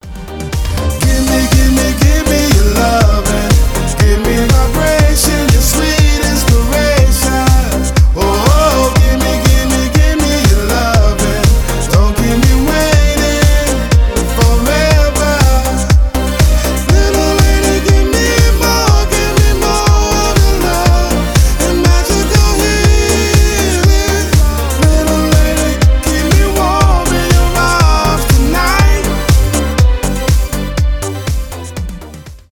Клубные » Танцевальные